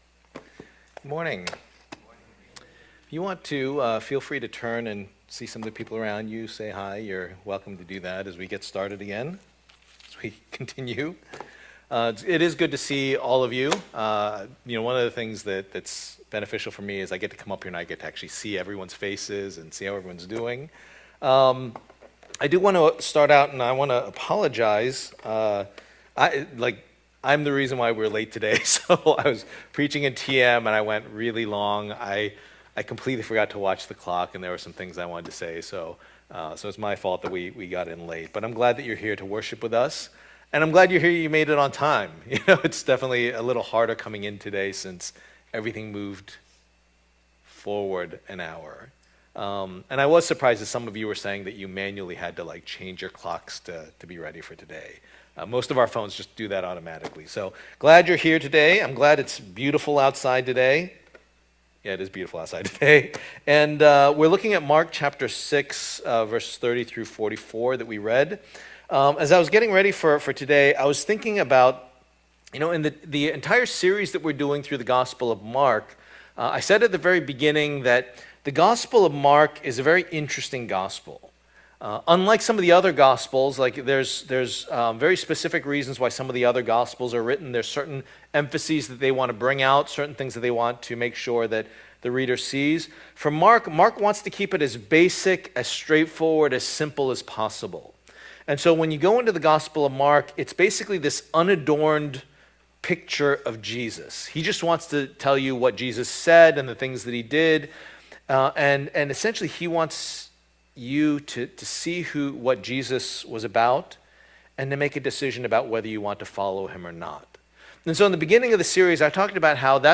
2026 Food and Following Preacher
The Gospel of Mark Passage: Mark 6:30-44 Service Type: Lord's Day « Faith that Heals The Messiah’s Mission